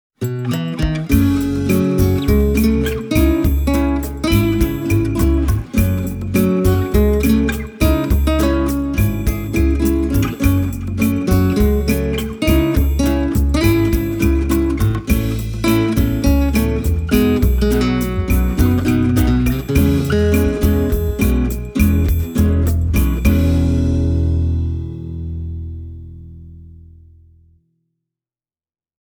Tässä on kyse herkästi resonoivasta, isoäänisestä soittimesta, jossa myös työnjälki on kiitettävällä tasolla.
Tässä kitarassa on kyllä niin viehättävän selkeä ja laulava ääni, että on jo melkein hankala lopettaa soittamista.
Manuel Rodriguez Clásica A on erittäin laadukas tapaus, joka näyttää hyvin kauniilta, ja joka soi erittäin kauniilla äänellä.